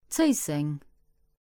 Cessange (Luxembourgish: Zéisseng, pronounced [ˈtsəɪseŋ]